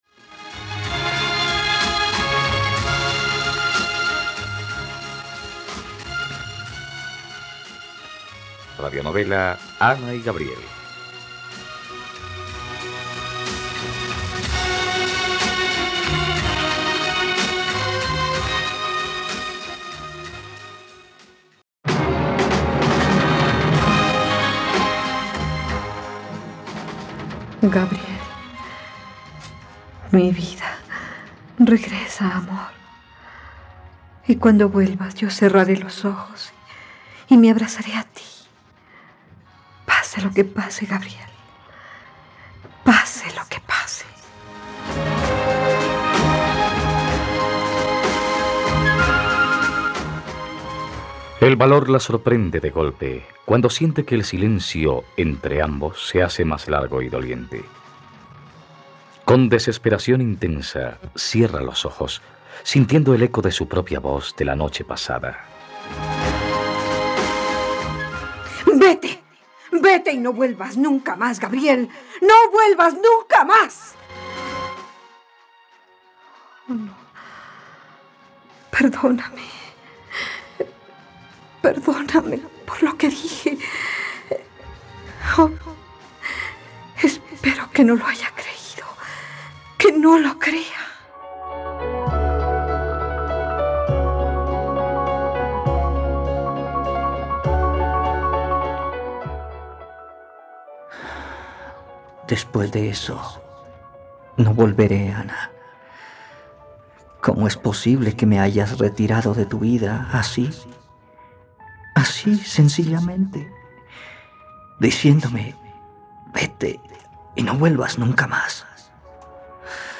..Radionovela. Escucha ahora el capítulo 70 de la historia de amor de Ana y Gabriel en la plataforma de streaming de los colombianos: RTVCPlay.